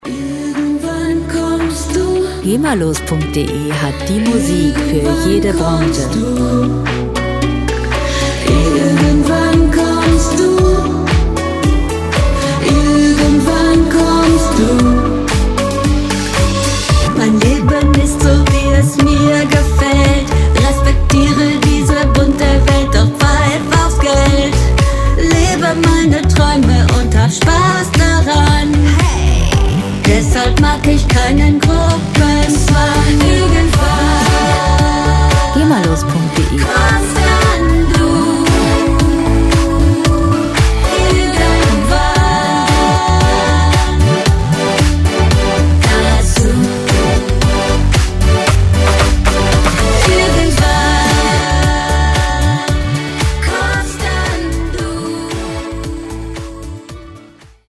Schlagerpop